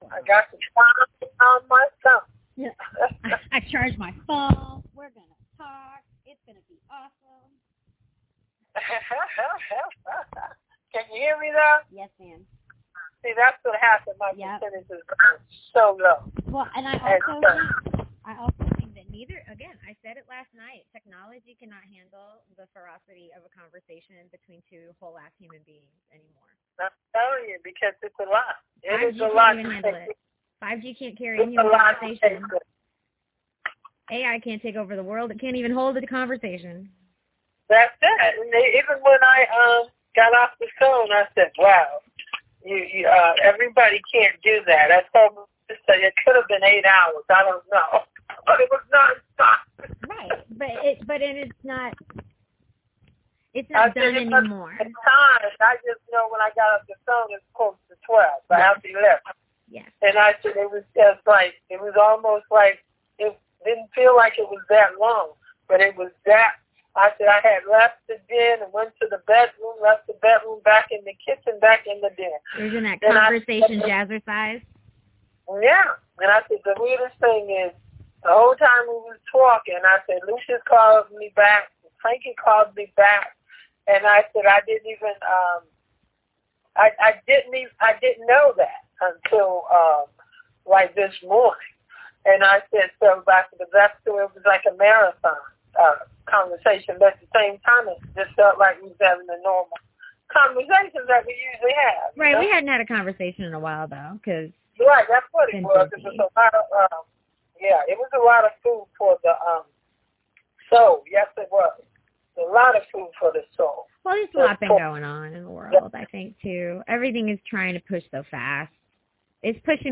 Episodes 2&3: One short raw conversation between two grown women...enjoy!